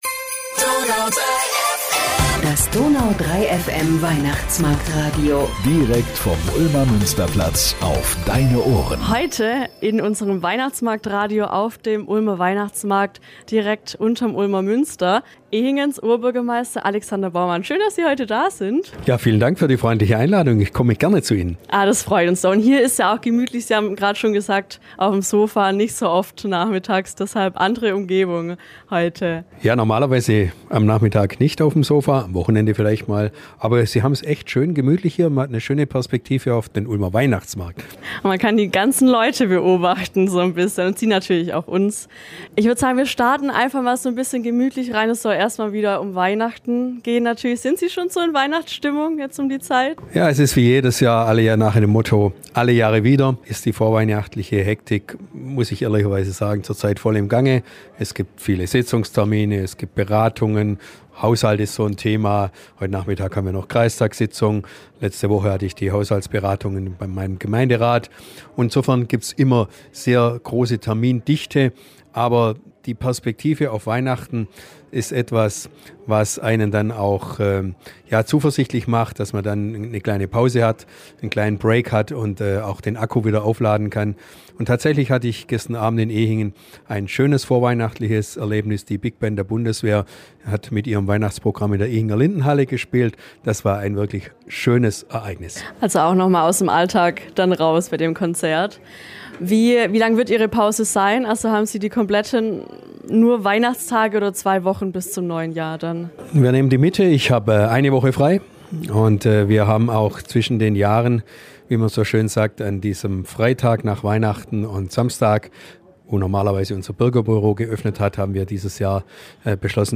Beschreibung vor 1 Jahr Im Interview erzählt Alexander Baumann wie sein Weihnachten aussieht, ob er überhaupt eine Pause hat und wie er das Ehinger Markplatz Open Air erlebt hat.